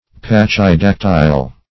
Search Result for " pachydactyl" : The Collaborative International Dictionary of English v.0.48: Pachydactyl \Pach`y*dac"tyl\, n. [Pachy- + dactyl.]